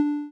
feat: added game audios
TimerTick.wav